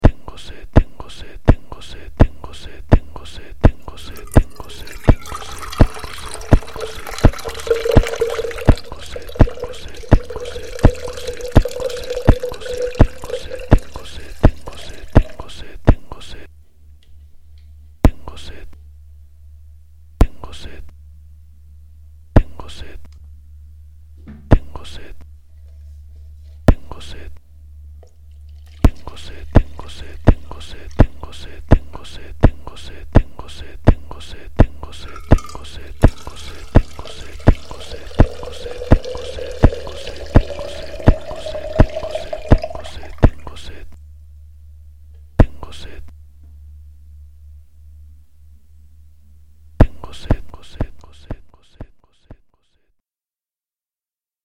Aunque no he grabado la palabra ANIMAL y tampoco lo he repetido verdaderamente, he realizado más bien un pequeño arte sonoro con la palabra TENGO SED y con la grabación de un chorro de agua que hice con 2 vasos en los que comunmente se calienta la leche (vasos de peltre).